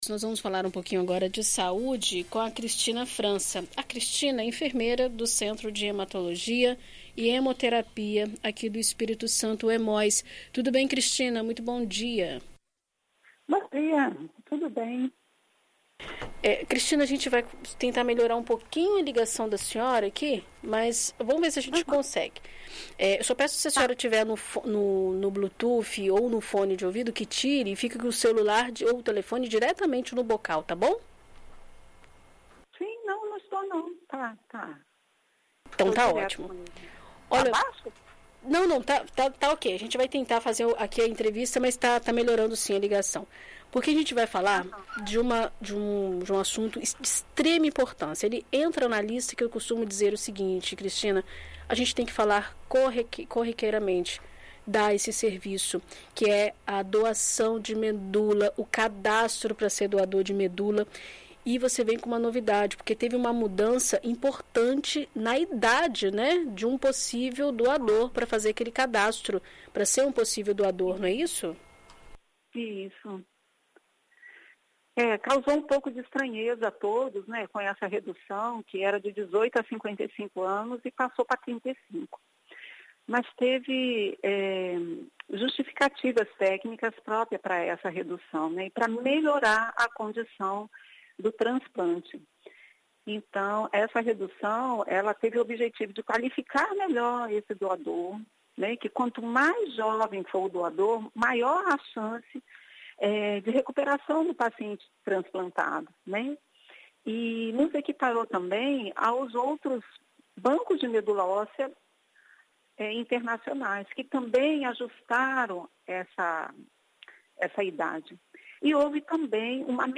A idade para doação de medula óssea agora é de 18 a 35 anos. A primeira busca de compatibilidade é na família do paciente, se não for encontrado se buscam outros doadores. Em entrevista à BandNews FM Espírito Santo